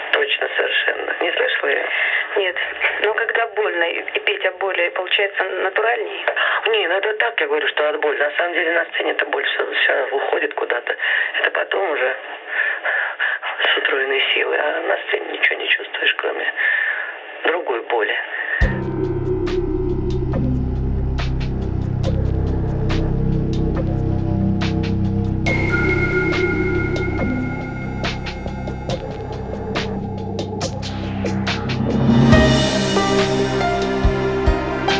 Жанр: Поп музыка / Русский поп / Русские